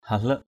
/ha-ləʔ/
halek.mp3